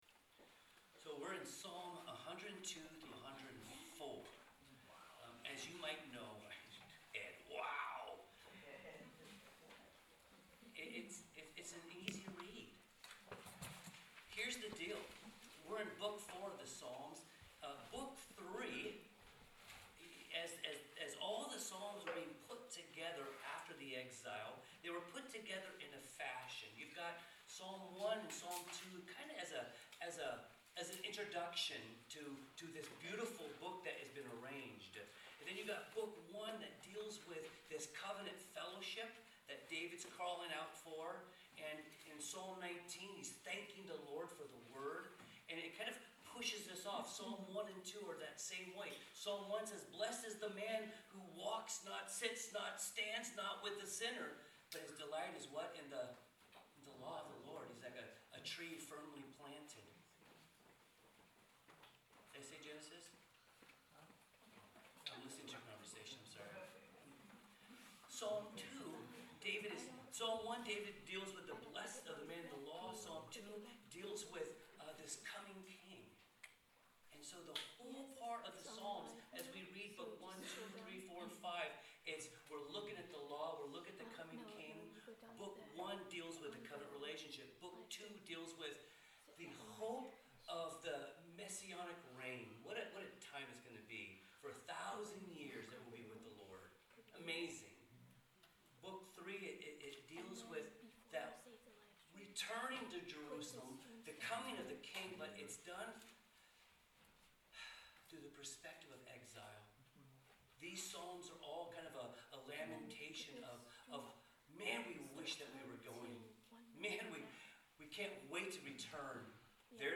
Sermons | Calvary Chapel Lighthouse Fellowship